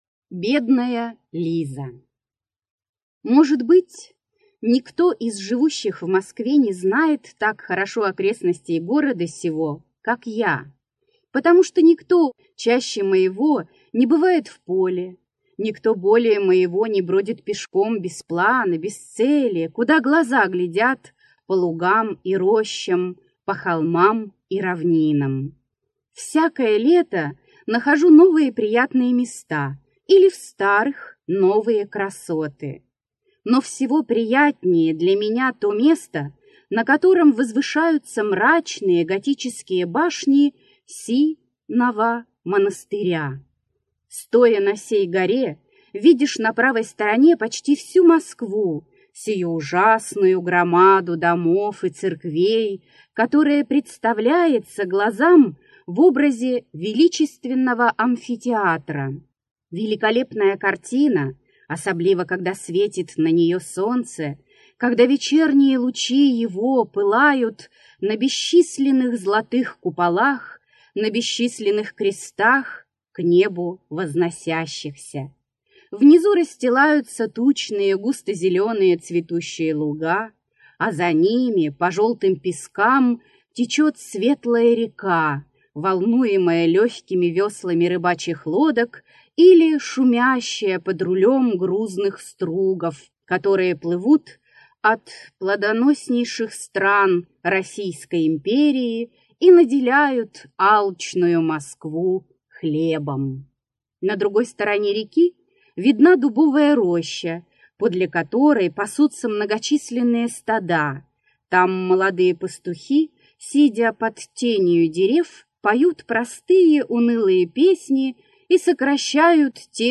Аудиокнига Бедная Лиза. Марфа-посадница, или покорение Новагорода | Библиотека аудиокниг